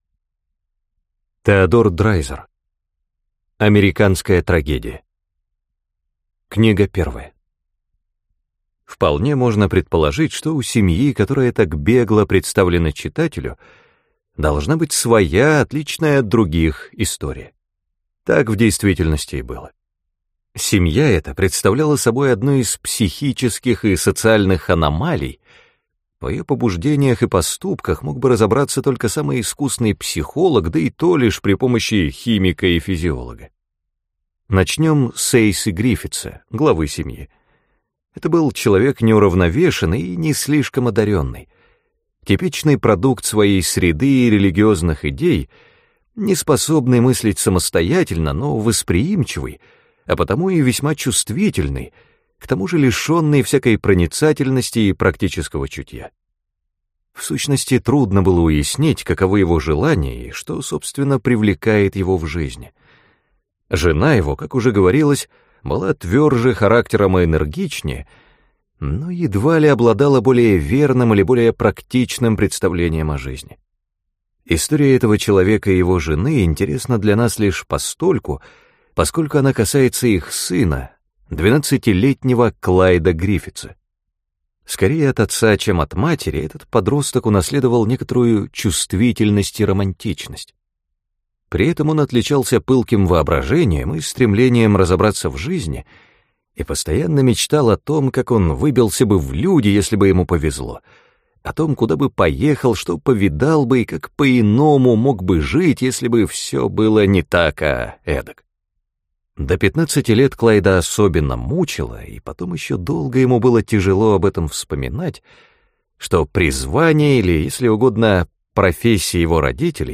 Аудиокнига Американская трагедия. Книга 1 | Библиотека аудиокниг